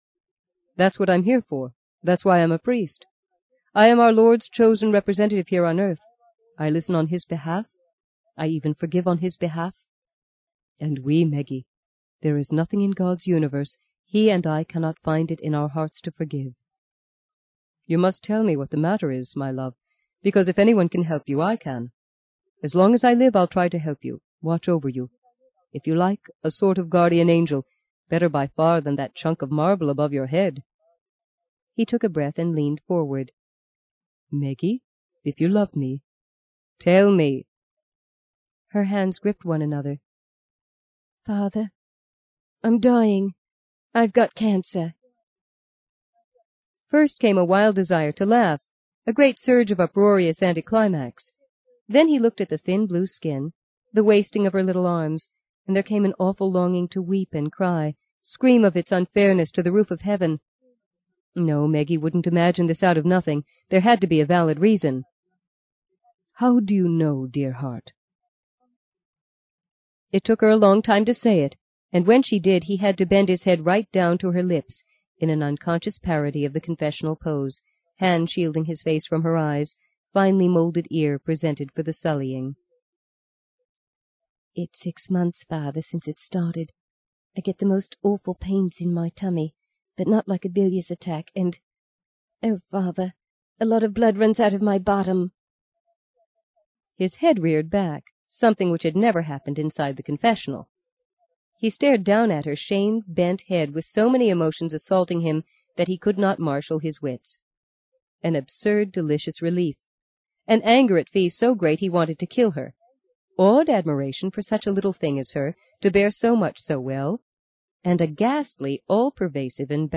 在线英语听力室【荆棘鸟】第六章 22的听力文件下载,荆棘鸟—双语有声读物—听力教程—英语听力—在线英语听力室